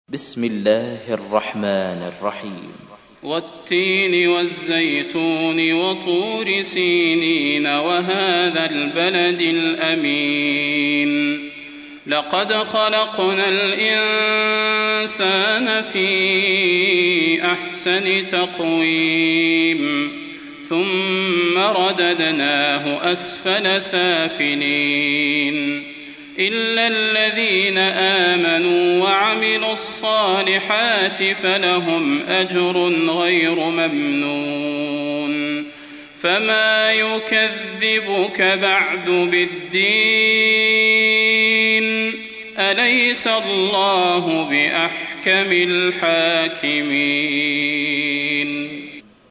تحميل : 95. سورة التين / القارئ صلاح البدير / القرآن الكريم / موقع يا حسين